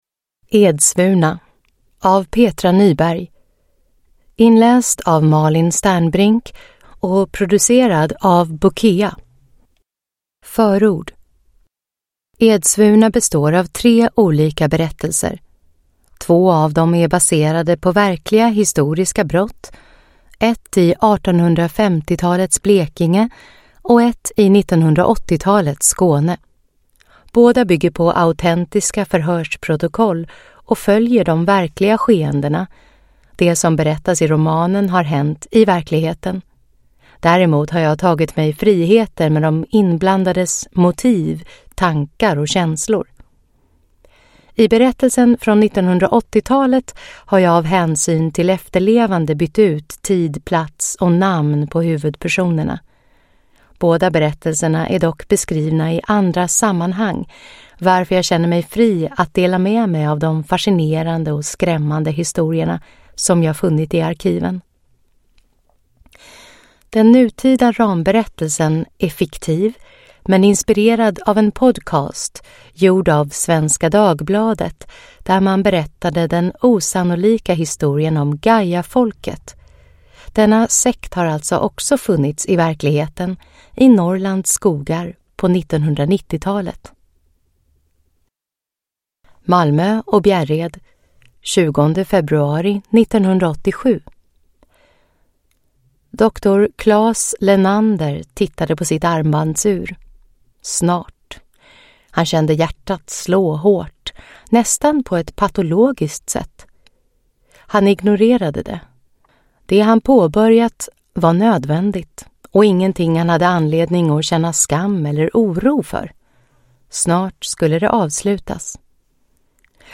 Edsvurna – Ljudbok